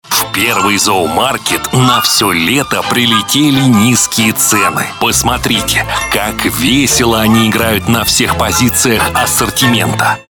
Муж, Пародия(Дроздов)
Звуковая карта: Auditnt id22 Микрофон: Neumann TLM 103 Преамп: Long voice master